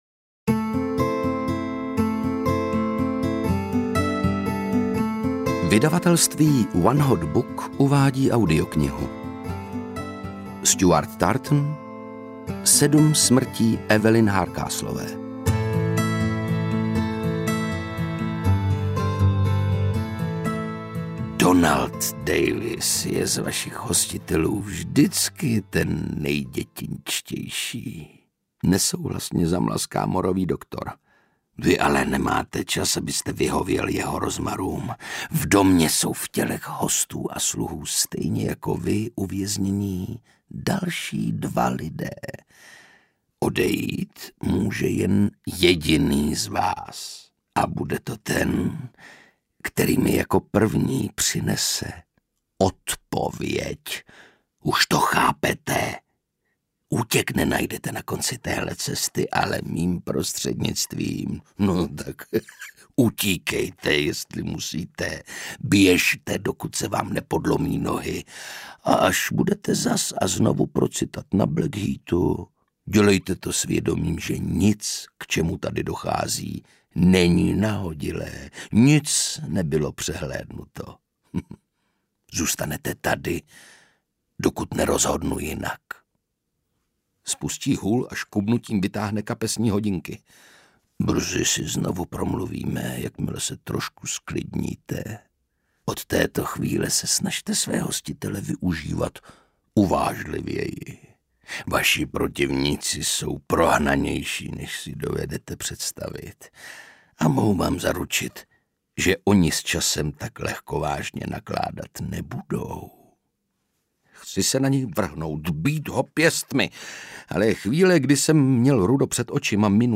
Sedm smrtí Evelyn Hardcastlové audiokniha
Ukázka z knihy
• InterpretOndřej Brousek